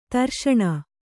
♪ tarṣaṇa